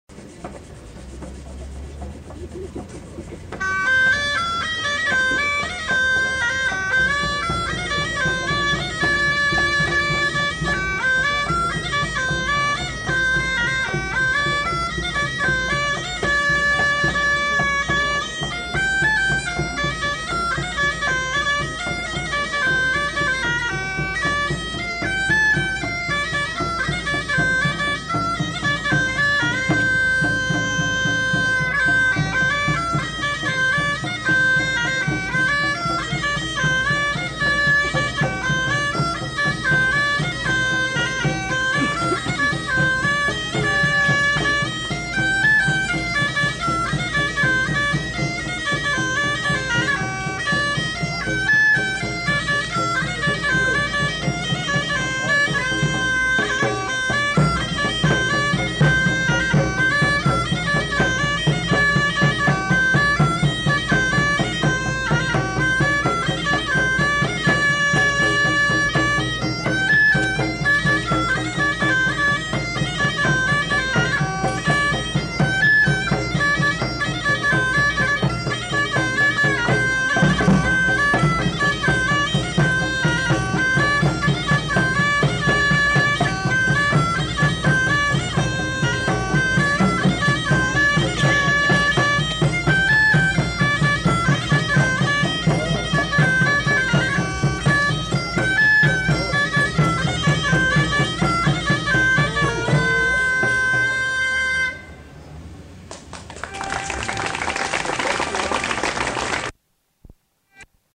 Bourrée
Aire culturelle : Pays d'Oc
Lieu : Pinerolo
Genre : morceau instrumental
Instrument de musique : cabrette ; grelot ; percussions
Danse : bourrée
Notes consultables : Le joueur de cabrette n'est pas identifié.